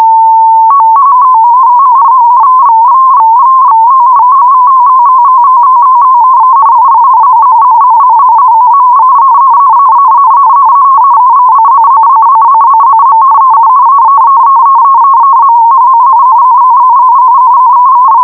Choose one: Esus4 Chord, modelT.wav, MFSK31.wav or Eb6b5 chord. MFSK31.wav